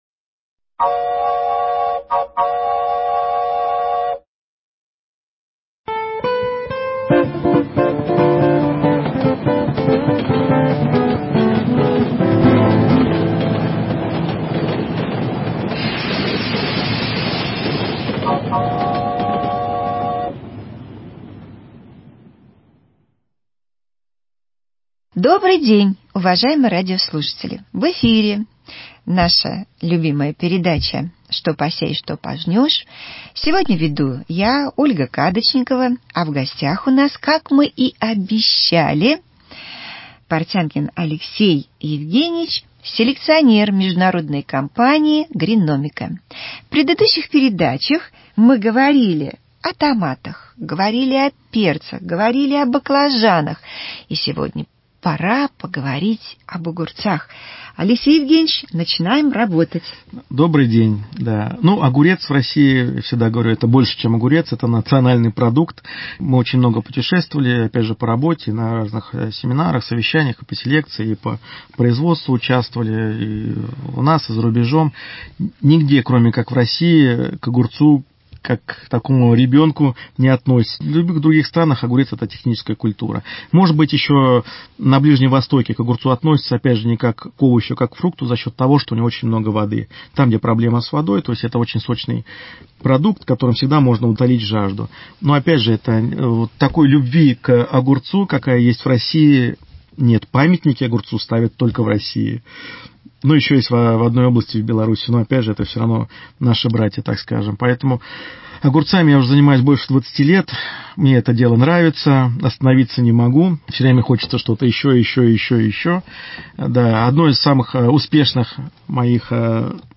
Передача для садоводов и огородников.